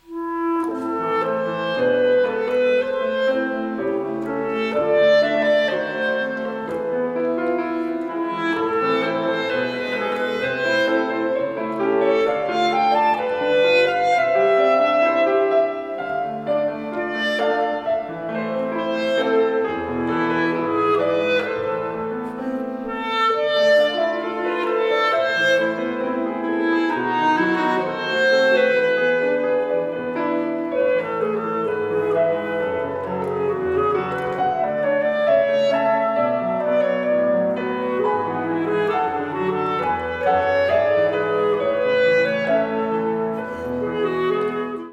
• Partition pour piano et clarinette